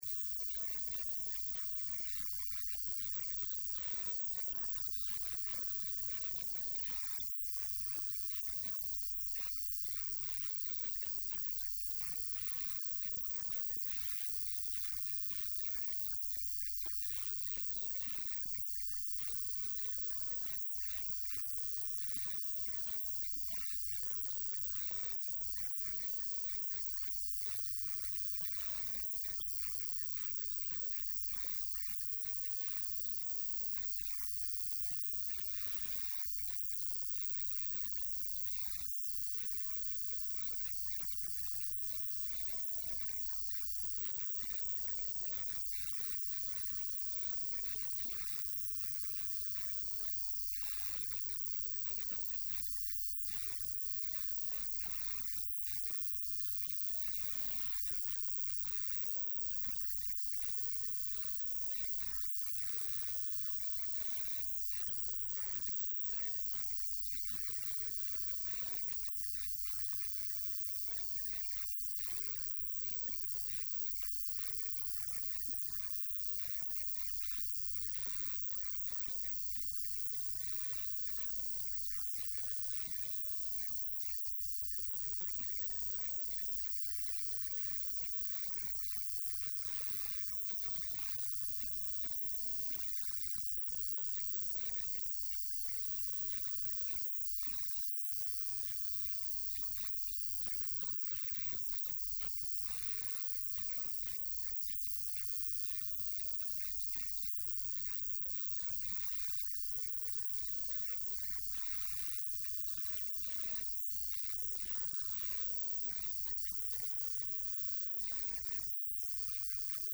mudane Xuseen Cabdi Cilmi Wasiirka ku xigeenka Wasaaradda Dastuurka xukumadda Faderaalka Soomaaliya ayaa wareysi uu siiyay Laanta afka soomaaliga ee VOA oo bahwadaag la ah Radio Muqdisho Codka Jamhuuriyadda ayaa tilmaamay in shaqooyinka badan ay qabatay Wasaaradda xukumadda Federaalka ah ee Soomaaliya iyadoo kaashanaysa haay’adaha ku lugta leh Dastuurka.